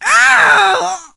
shelly_die_03.ogg